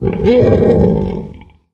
boar_panic_3.ogg